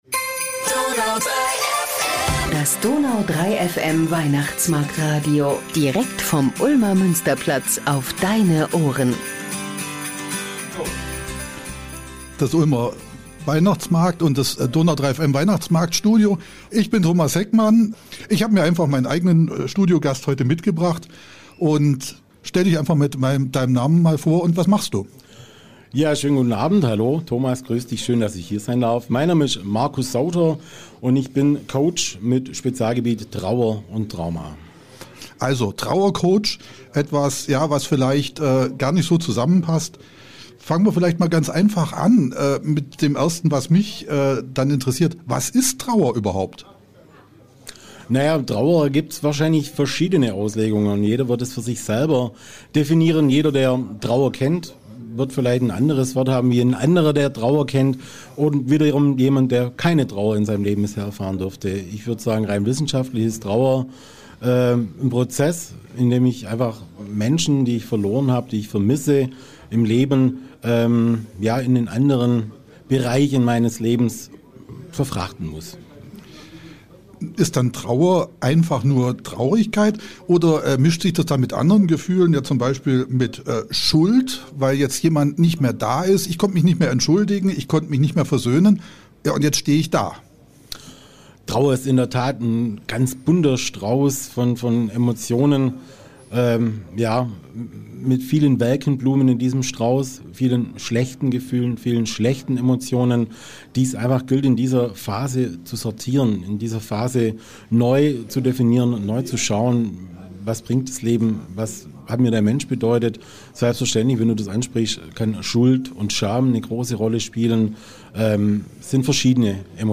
in unserem Weihnachtsmarktradio